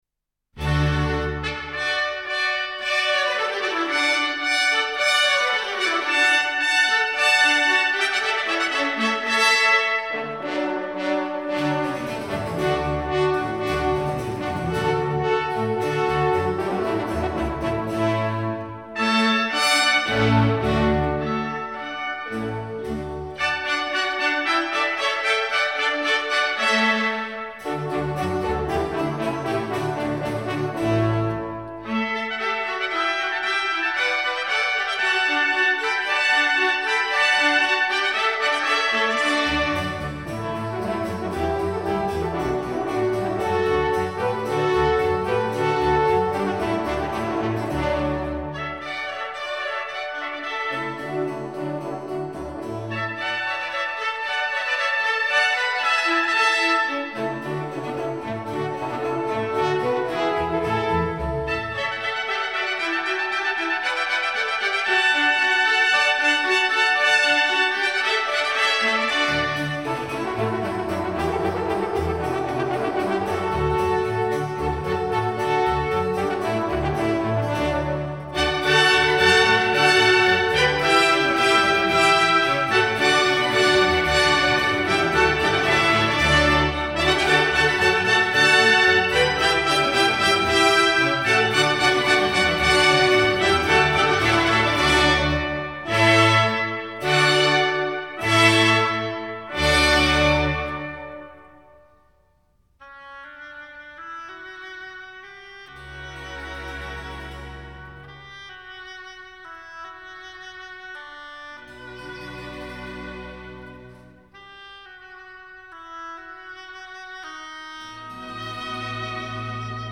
suite en Re majeur